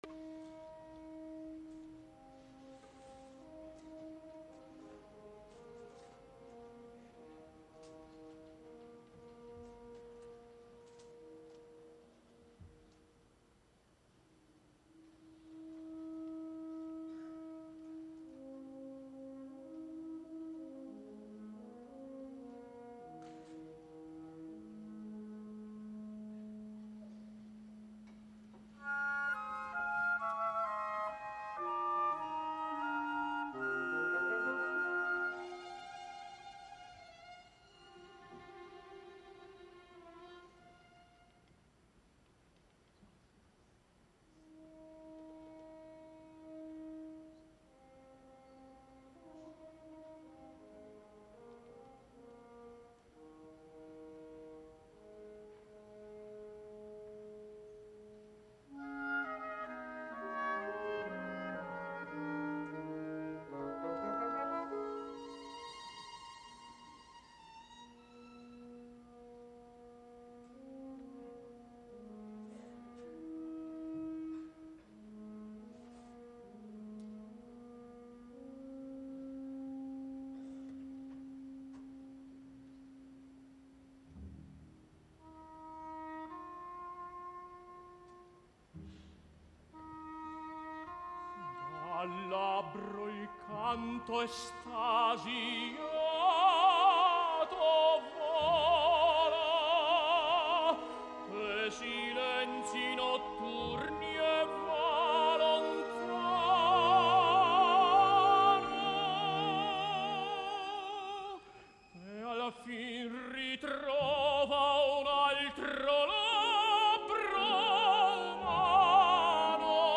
FALSTAFF A LA SCALA 2013 | IN FERNEM LAND